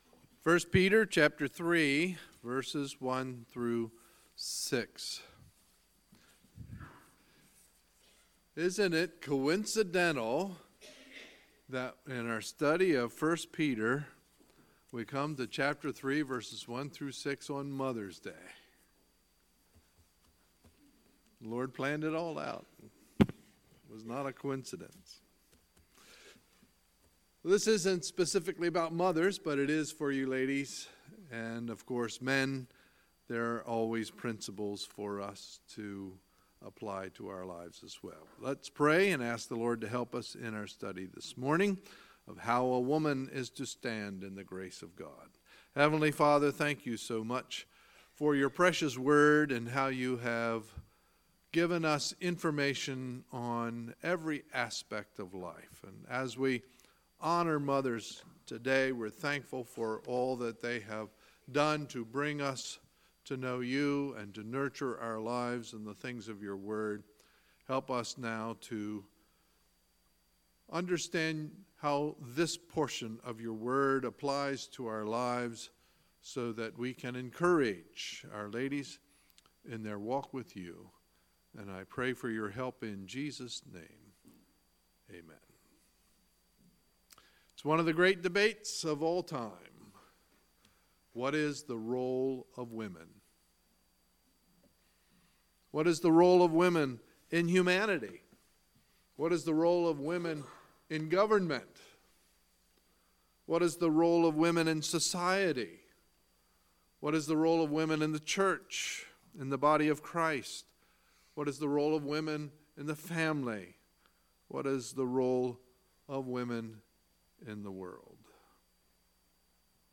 Sunday, May 13, 2018 – Sunday Morning Service